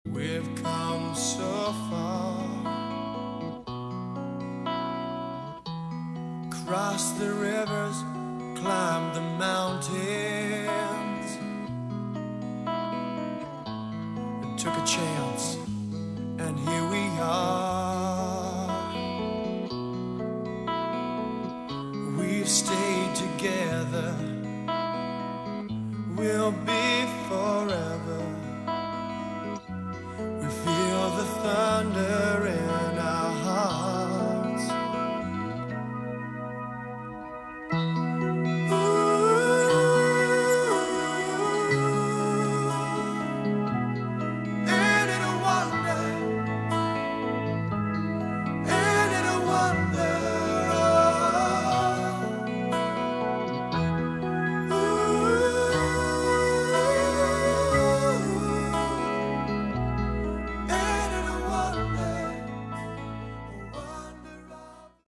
Category: AOR
vocals
bass
drums
keyboards